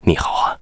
文件 文件历史 文件用途 全域文件用途 Bk2_tk_01.ogg （Ogg Vorbis声音文件，长度0.6秒，152 kbps，文件大小：12 KB） 源地址:游戏语音 文件历史 点击某个日期/时间查看对应时刻的文件。